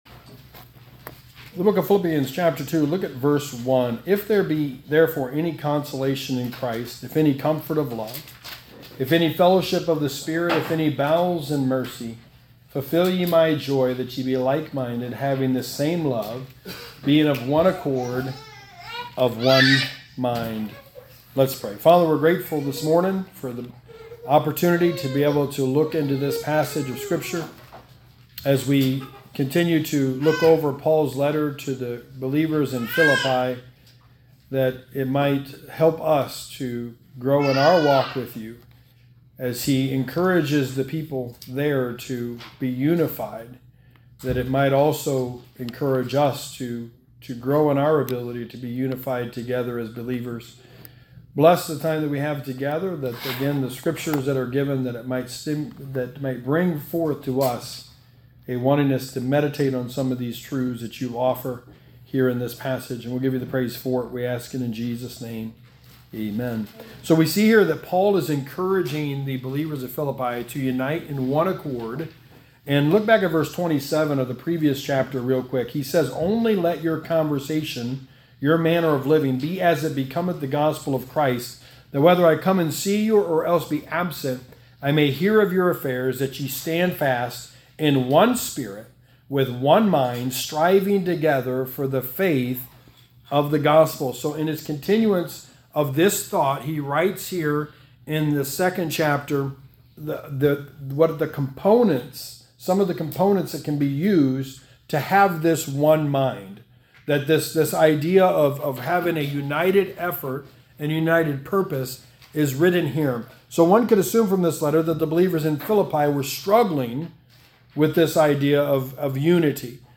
Sermon 10: The Book of Philippians: The Need for Order
Service Type: Sunday Morning